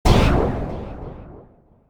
Royalty-free sound effects and music beds that came with iMovie and the iLife suite back in the day for use in videos.
Synth Zap Impact.m4a